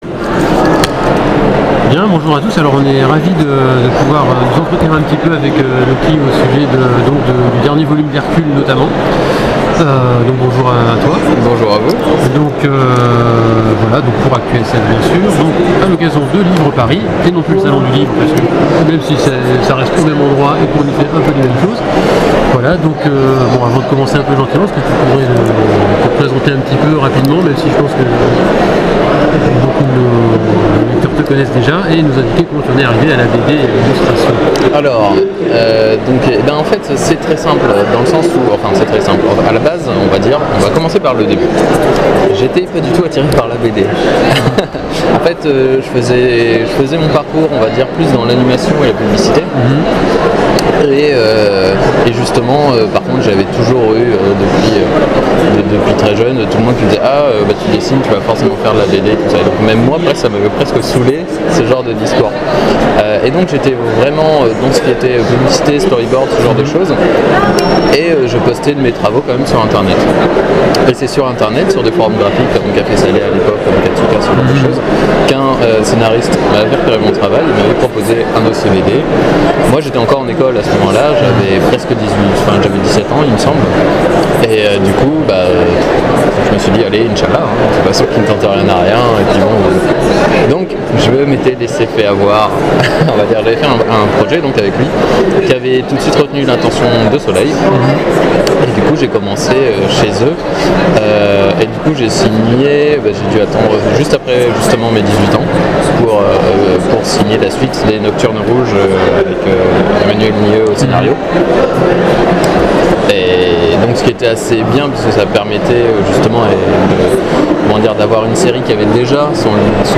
Interview 2017